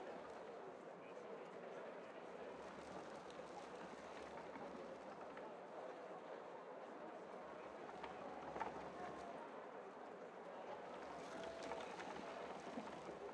sfx_amb_map_settlement.ogg